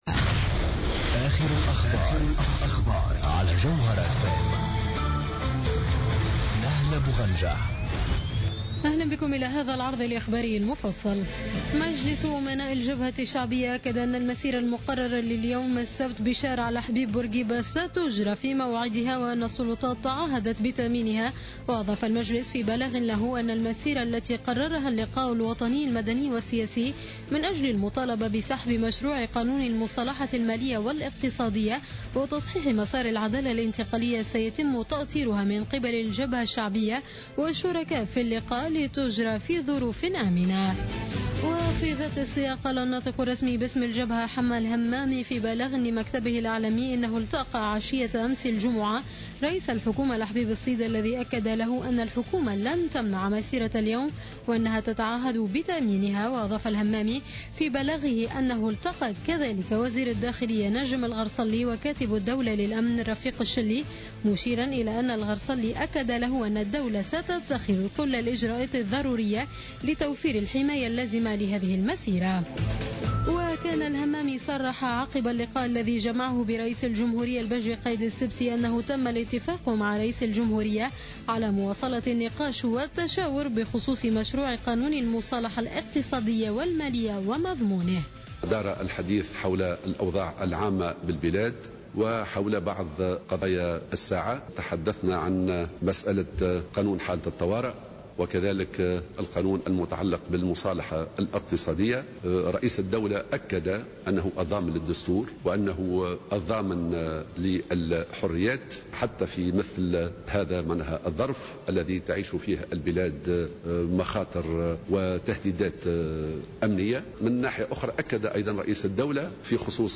نشرة أخبار منتصف الليل ليوم السبت 12 سبتمبر 2015